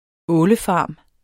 Udtale [ ˈɔːlə- ]